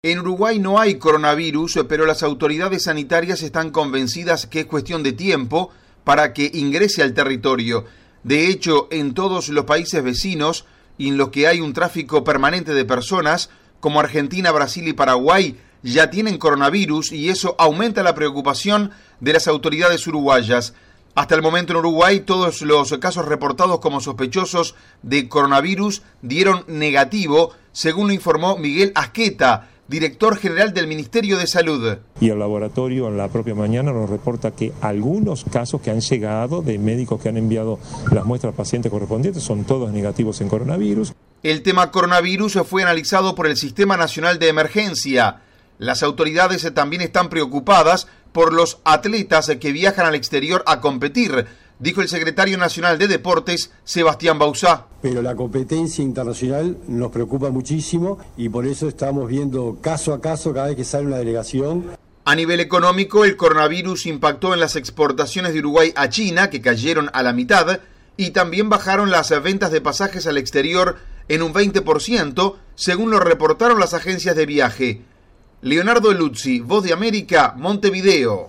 VOA: Informe de Uruguay